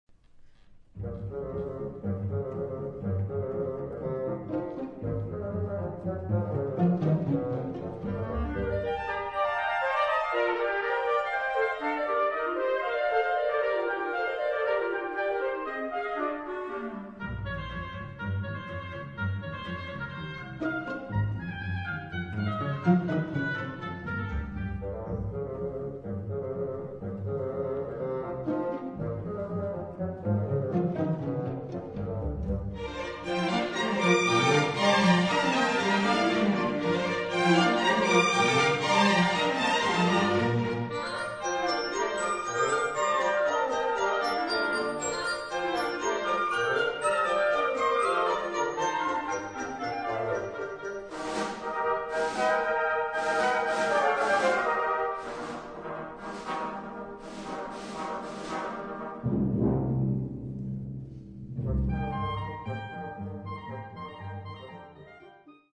III.Allegro